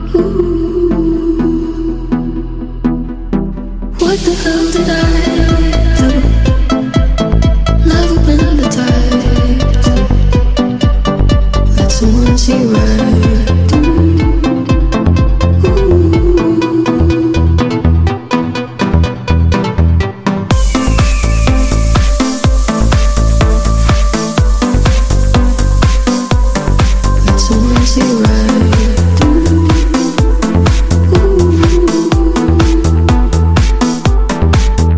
Жанр: Хаус
# House